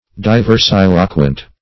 Search Result for " diversiloquent" : The Collaborative International Dictionary of English v.0.48: Diversiloquent \Di`ver*sil"o*quent\, a. [L. diversus diverse + loquens, p. pr. of loqui to speak.] Speaking in different ways.
diversiloquent.mp3